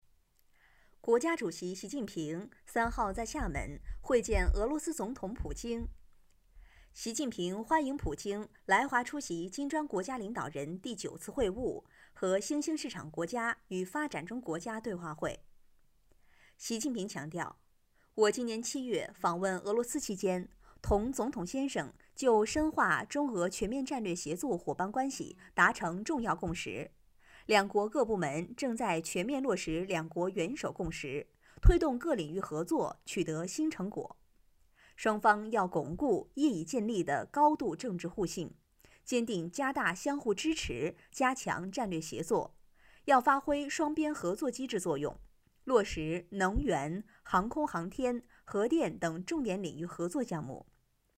• 9央视女声2号
新闻播报-温婉大气